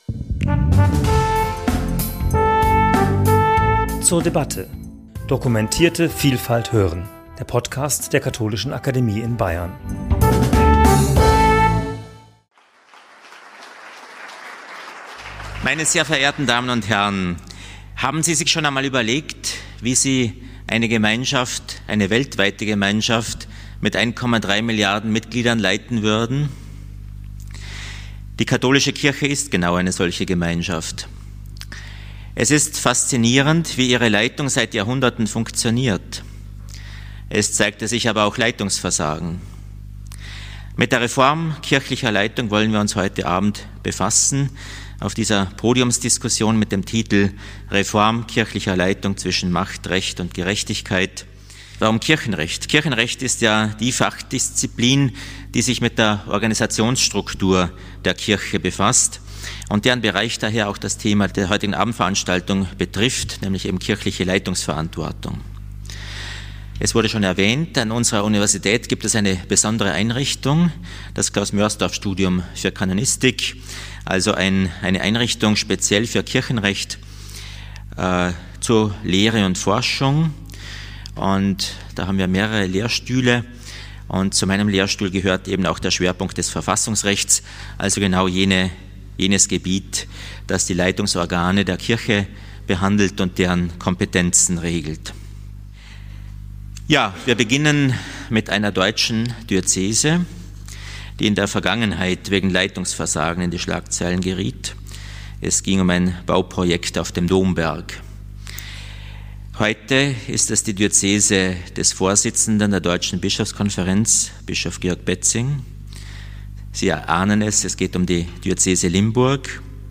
Der Synodale Weg in Deutschland formulierte konkrete Strukturreformen wie z. B. dezentrale Verwaltungsgerichte und die Neugewichtung Diözesaner Räte. Auf dem Podium diskutieren Fachleute aus Kirchenrecht, Politikwissenschaft und der Praxis diözesaner Verwaltung.